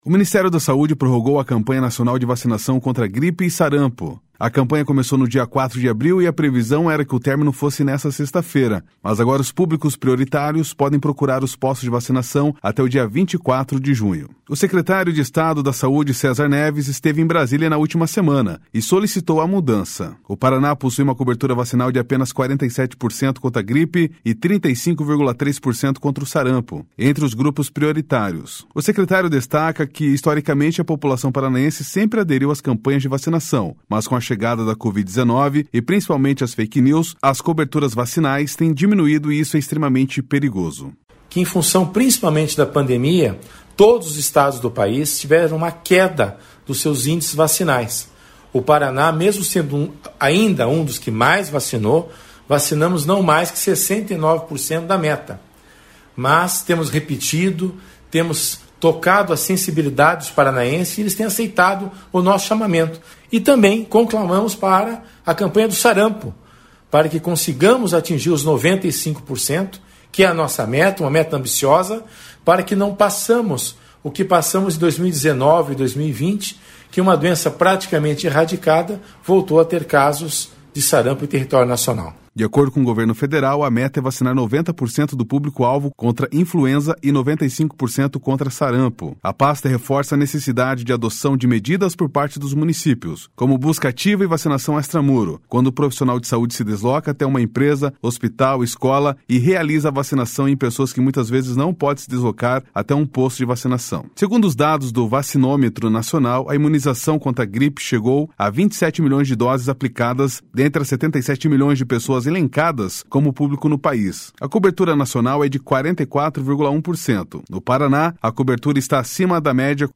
O secretário destaca que historicamente a população paranaense sempre aderiu às campanhas de vacinação, mas com a chegada da Covid-19 e principalmente as fake news, às coberturas vacinais têm diminuído e isso é extremamente perigoso.//SONORA CÉSAR NEVES.//